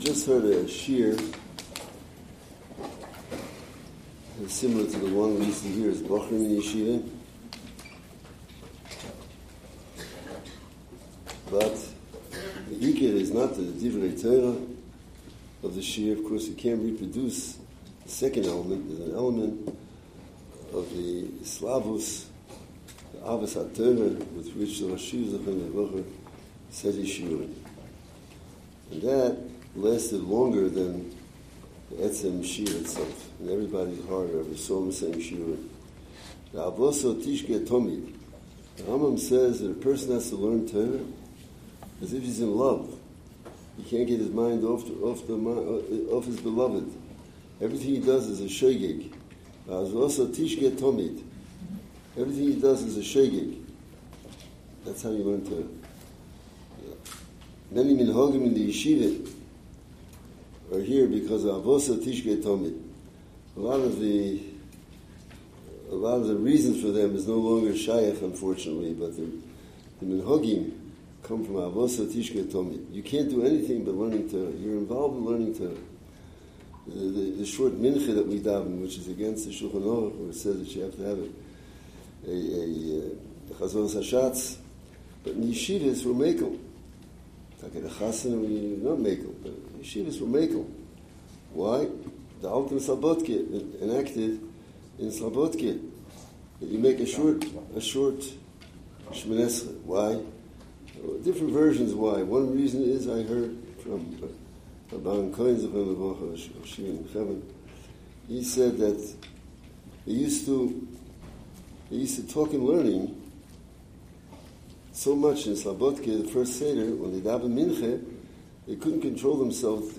On the last Motzei Shabbos of the long winter Zman, the Yeshiva held a Tzeischem L’sholom Melaveh Malka for the Rosh Hayeshiva shlit”a who was leaving for Eretz Yisroel for Yom Tov.